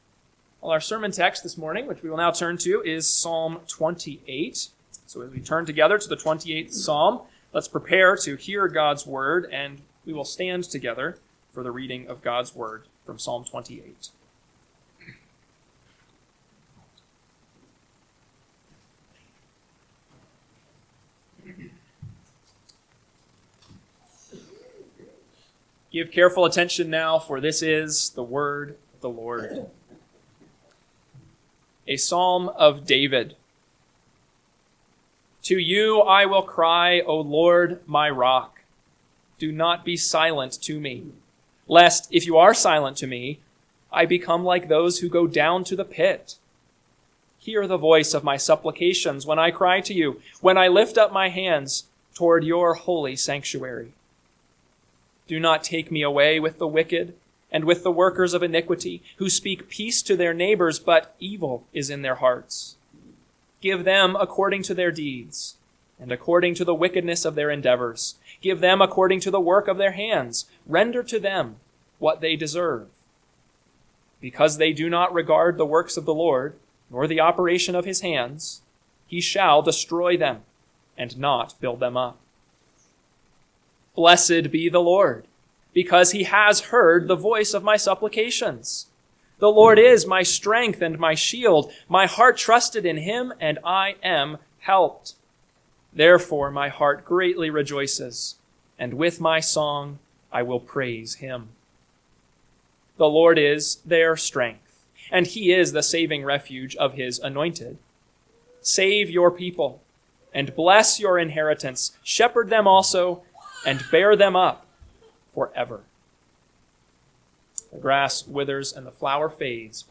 AM Sermon – 8/10/2025 – Psalm 28 – Northwoods Sermons